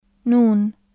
日本語の「ナ・ニ・ヌ」と同じように，舌先を歯茎のあたりにつけた状態で，鼻から息を抜いて発音する/n/の音です。
/n/ 有声・歯茎・鼻音/n/ ن /nuːn/ n （アルファベット） ناس /naːs/ 人々 أين /ʔayna/ どこ نيء /niːʔ/ 生（なま）の جانب /jaːnib/ ～のそば，側 نور /nuːr/ 光 جنوب /januːb/ 南 من /min/ ～から من /man/ だれ